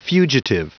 Prononciation du mot fugitive en anglais (fichier audio)
Prononciation du mot : fugitive